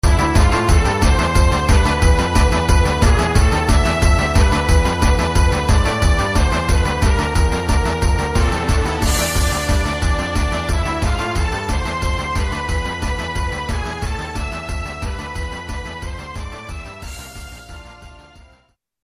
The algorithm goes through all available samples and reduces the amplitude by a percentage value.
(mp3) The Extreme (with Fade)